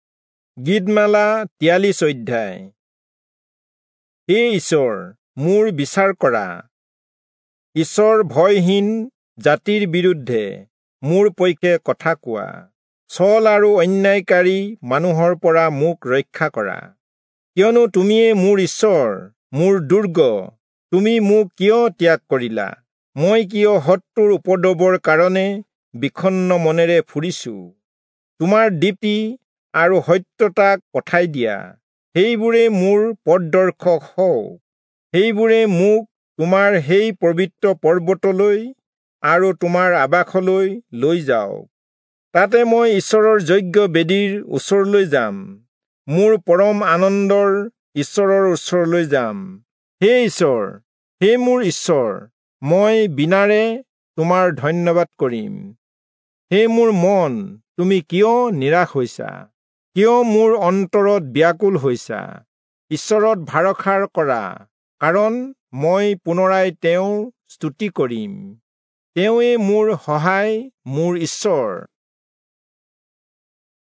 Assamese Audio Bible - Psalms 6 in Nlv bible version